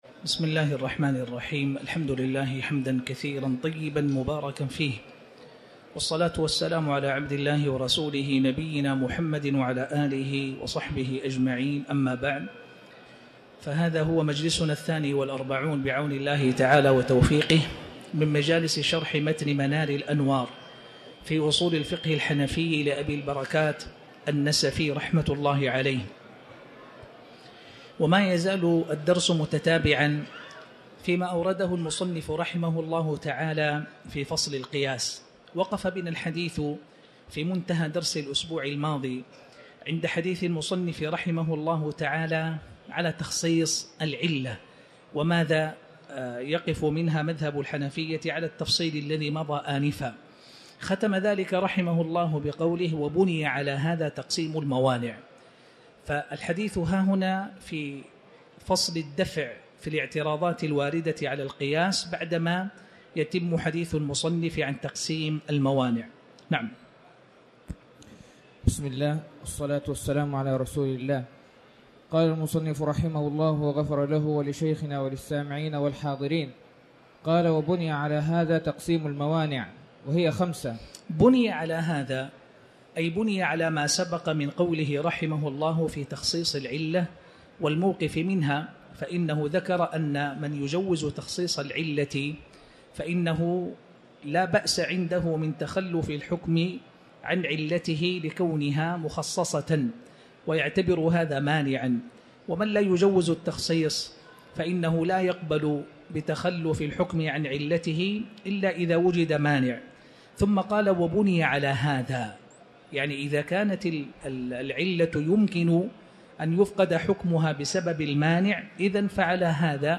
تاريخ النشر ٢٦ ربيع الثاني ١٤٤٠ هـ المكان: المسجد الحرام الشيخ